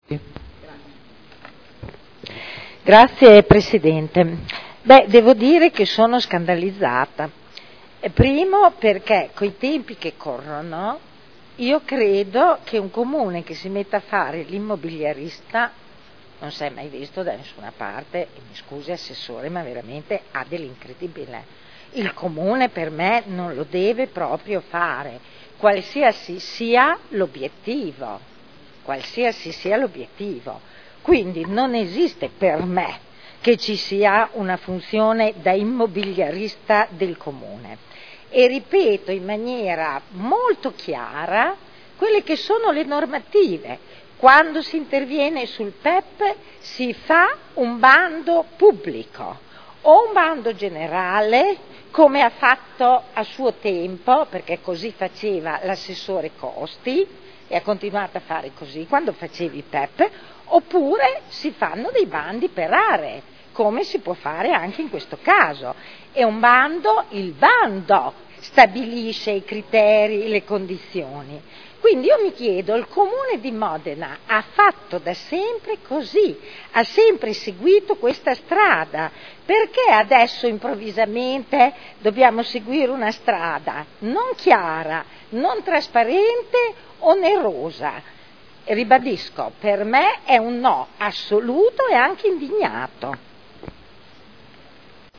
Seduta del 22/12/2011. Dichiarazione di voto su proposta di deliberazione. Conferimento alla Società di Trasformazione Urbana CambiaMo S.p.A. di lotto edificabile presso il comparto Ex Mercato Bestiame – Approvazione